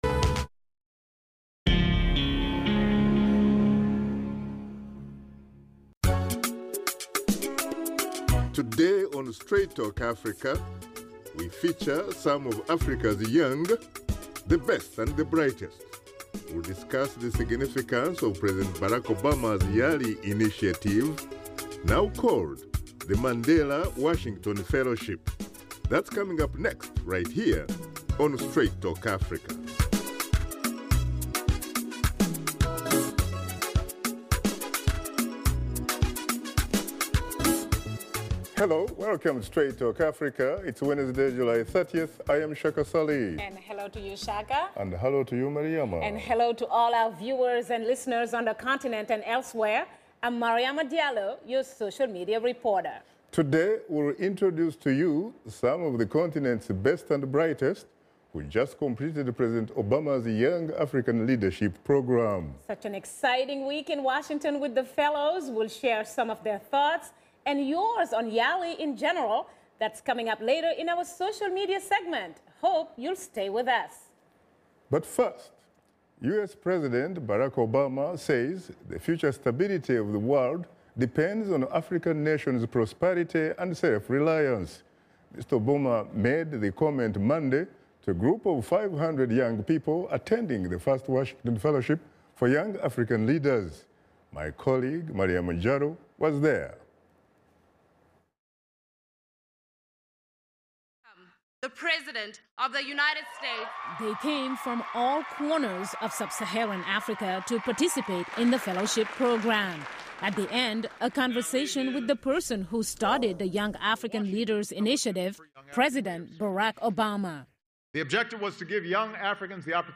Washington Studio Guests
PreTaped Interview